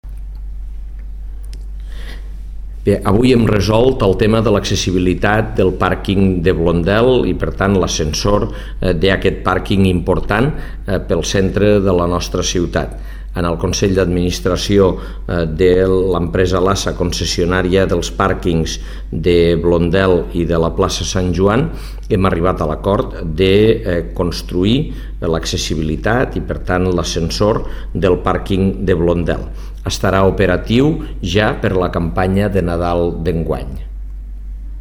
Compartir Facebook Twitter Whatsapp Descarregar ODT Imprimir Tornar a notícies Fitxers relacionats ARXIU DE VEU on Ros explica l'acord d'ubicació de l'ascensor (544.1 KB) T'ha estat útil aquesta pàgina?